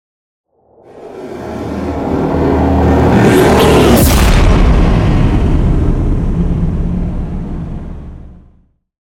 Dramatic electronic whoosh to hit trailer
Sound Effects
Fast paced
In-crescendo
Atonal
dark
futuristic
intense
tension
woosh to hit